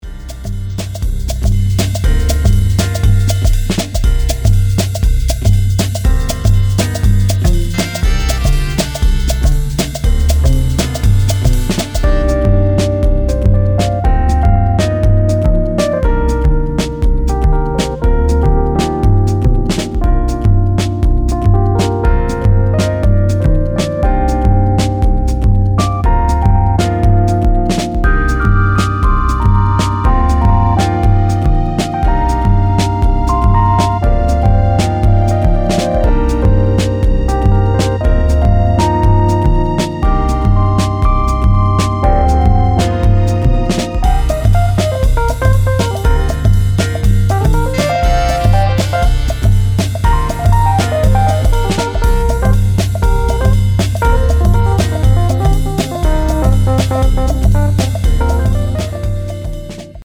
生演奏をたっぷりと用いながら豊かな音楽性を湛えたモダンなジャズ/フュージョン・ハウス〜ブレイクビーツを繰り広げています。